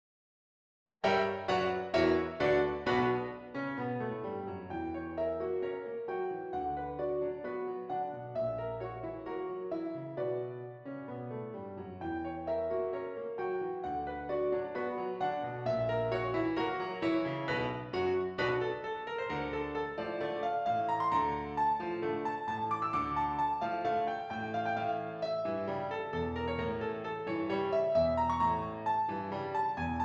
B Minor
Tango